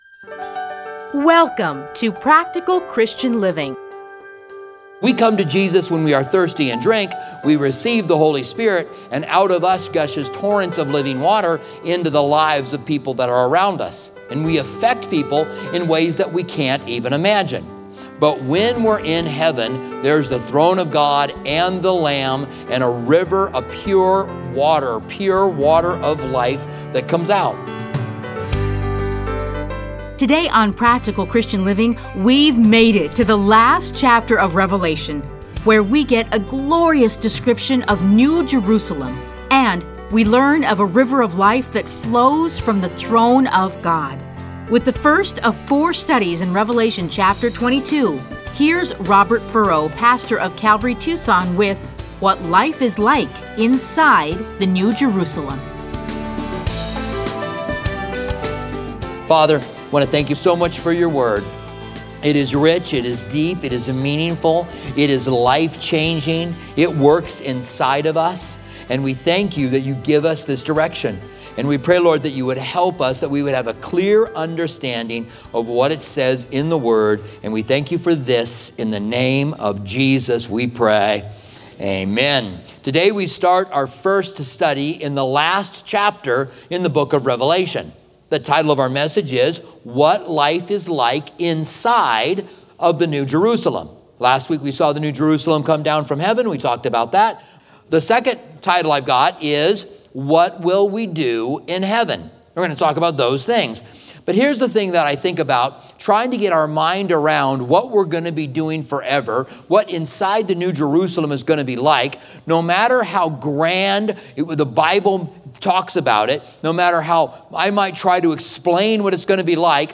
Listen to a teaching from Revelation 22:1-5.